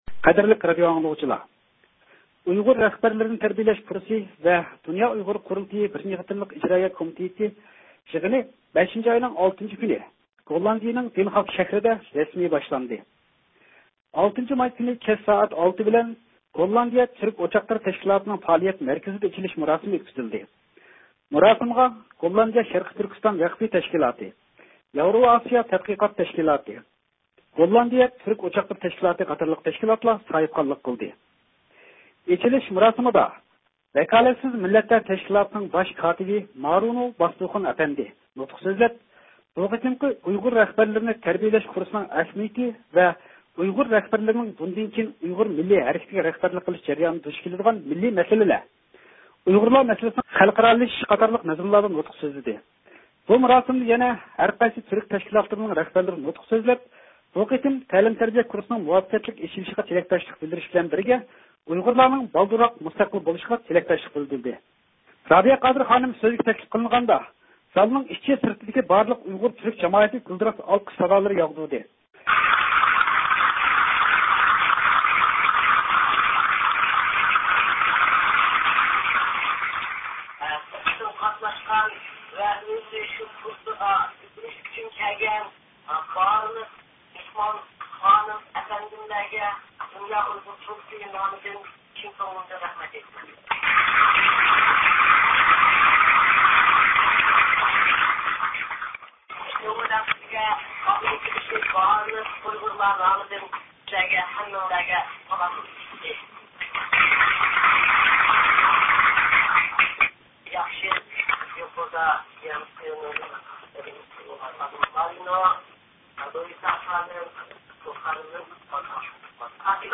دۇنيا ئۇيغۇر قۇرۇلتېيى رەئىسى رابىيە قادىر خانىم قاتارلىقلار مۇھىم سۆزلەرنى قىلدى .